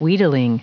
Prononciation du mot wheedling en anglais (fichier audio)
Prononciation du mot : wheedling